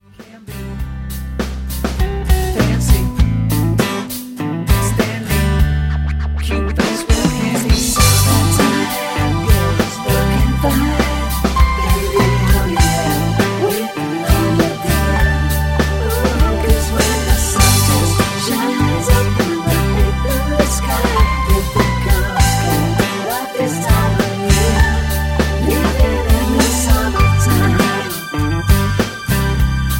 Db
Backing track Karaoke
Pop, Rock, 2000s